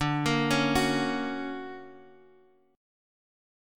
D+M7 chord